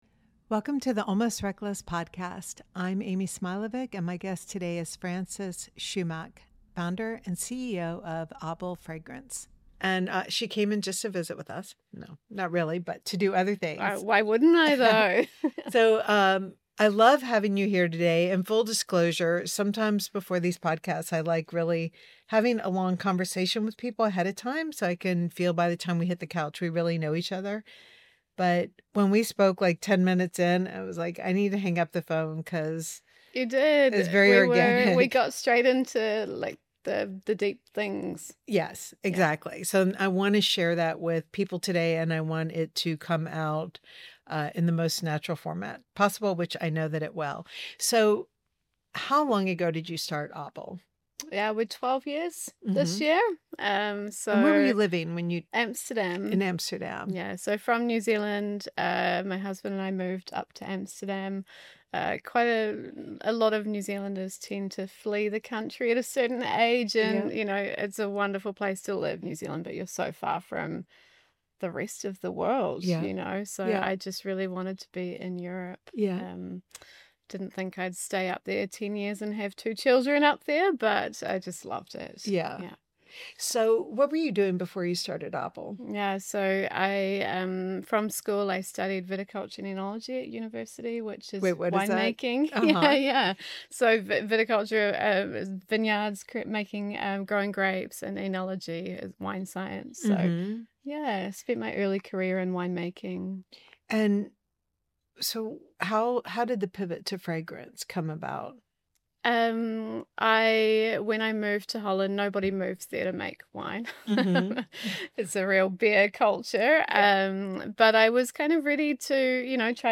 The discussion covers a range of topics, from the significant influence of mentors at a young age and the investor/founder relationship, to the inner-workings of running a business with your significant other. The episode was shot and produced live in the Tibi Atelier.